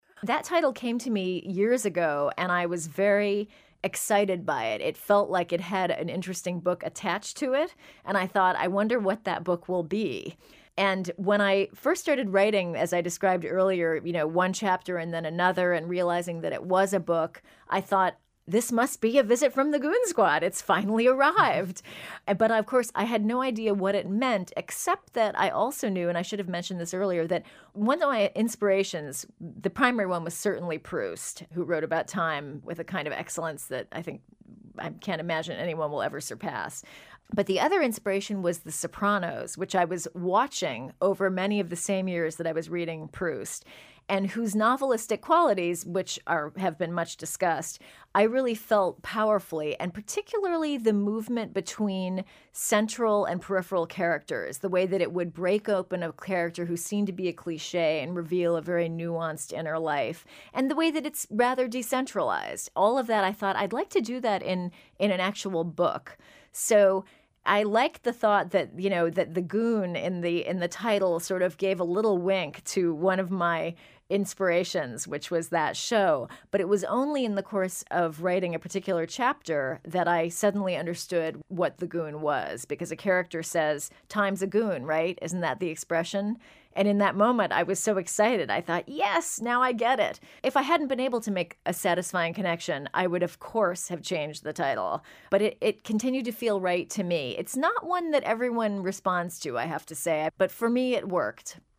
Transcript of conversation with Jennifer Egan